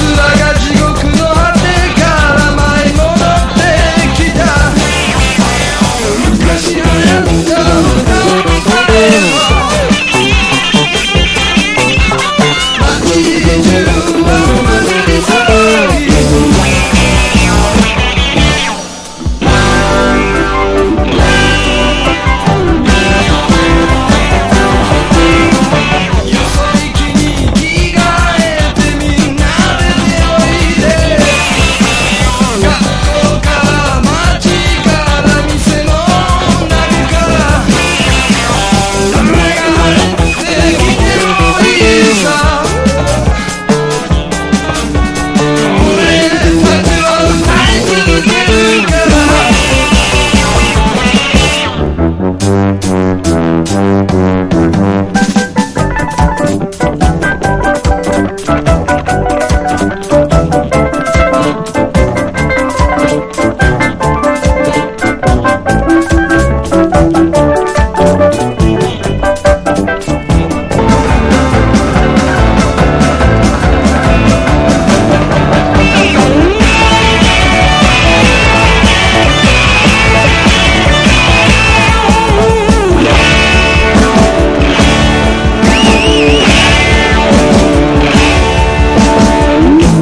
B面は意表を付く展開のフュージョンテイストのボーカル曲。